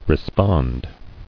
[re·spond]